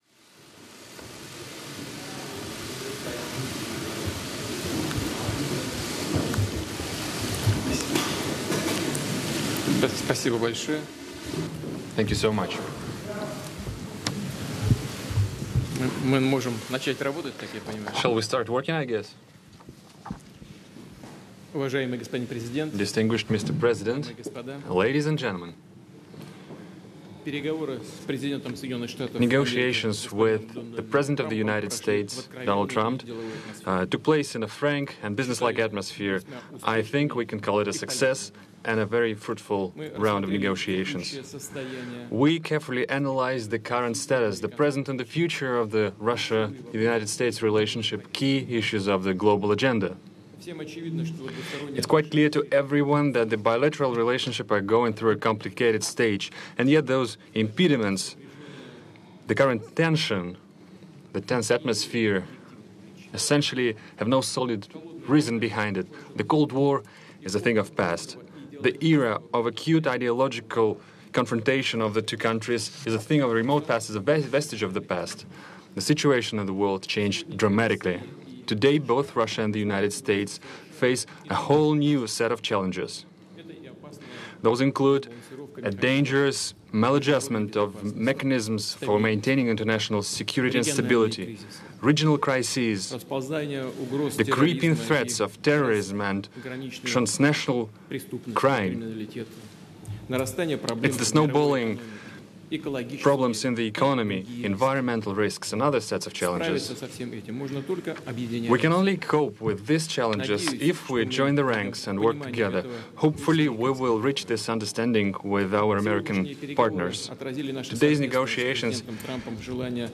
U.S. President Donald Trump and Russian President Vladimir Putin hold a joint news conference after their two-hour meeting in Helsinki. Putin reiterates that Russia never interfered in the U.S. 2016 presidential election and says he told President Trump he will look into the supposed twelve Russian spies indicted by the U.S. for election meddling.
Putin also says that Russia has no compromising material on President Trump or his family. Putin's remarks are translated from Russian. Held in Helsinki, Finland.